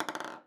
marble_tic_wood_1.wav